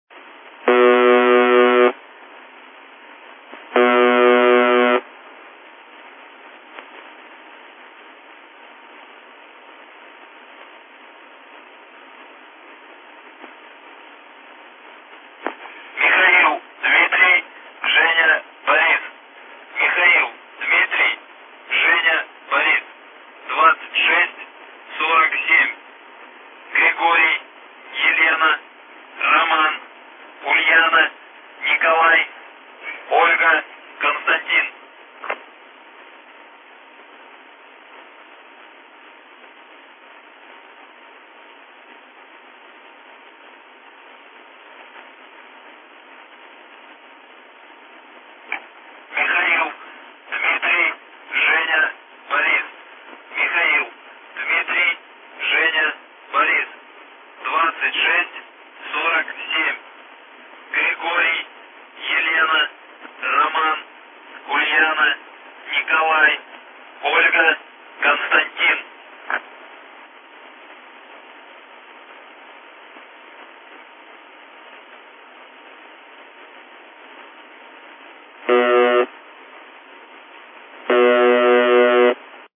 방송은 기본적으로 1.2초 동안 지속되고 1~1.3초 동안 멈추는 윙윙거리는 소리를 분당 21~34회 반복하여 전달한다.[76][44][47] 이 부저 소리는 적어도 1982년부터 방송된 것으로 확인되며,[76] 처음에는 2초 간격으로 짧은 전자음을 반복하는 형태였으나 1990년 초에 현재와 같은 부저음으로 바뀌었다.[79][80][49][50]
부저음 중간중간에 러시아어로 된 음성 메시지가 방송되기도 하며,[44][47][48] 때로는 부저음 뒤로 희미한 잡음이나 사람들의 대화 소리가 들리는 경우도 있다.[55][56] 이는 방송이 미리 녹음된 것을 재생하는 것이 아니라, 마이크가 켜진 상태에서 부저 발생 장치의 소리를 직접 송출하는 생방송 형태이기 때문으로 추정된다.[55][56] 방송 내용은 시간이 지남에 따라 변화를 보이기도 했다.
UVB-76 - "부저"와 음성 메시지, 2013년 1월 24일 녹음
음성 메시지는 항상 실제 사람의 목소리로 러시아어로 전달되며,[84] 일반적으로 다음 세 가지 고정된 형식을 따른다.[1][39][13][14]